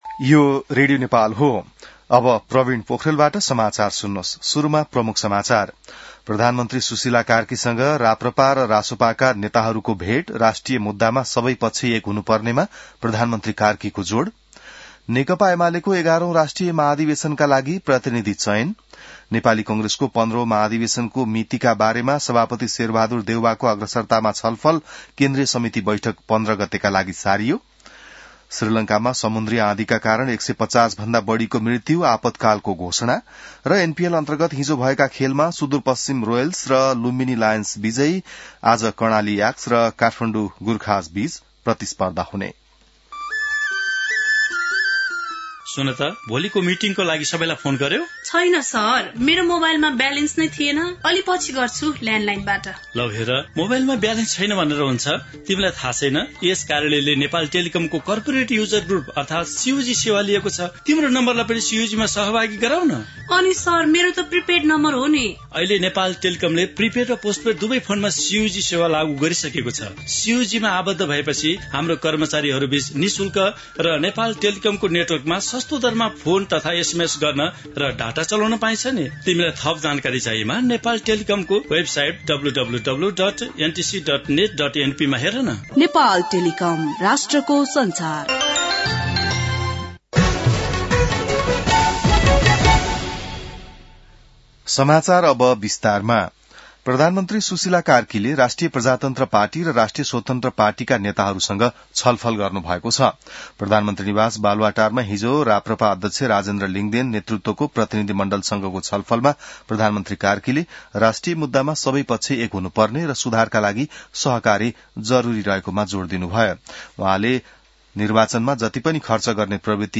बिहान ७ बजेको नेपाली समाचार : १४ मंसिर , २०८२